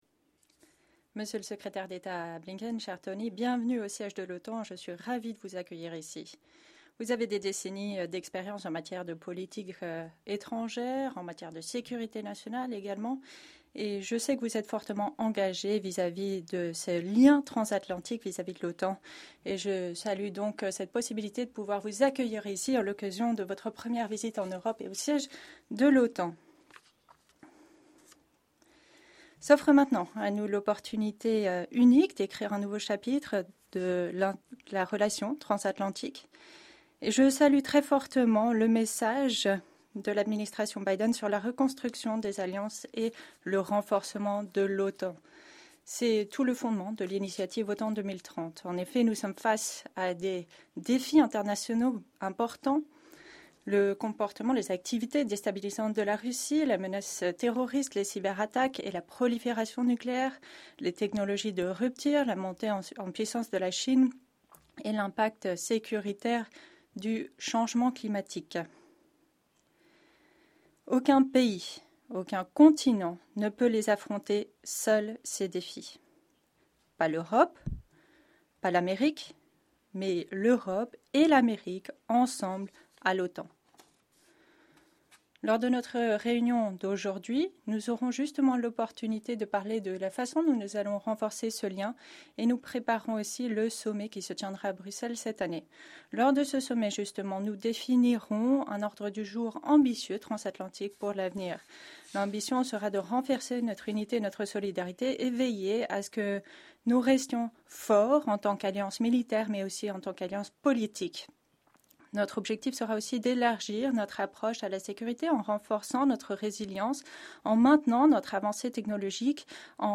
Short remarks
by NATO Secretary General Jens Stoltenberg and US Secretary of State Antony Blinken at the start of the NATO Foreign Ministers' meetings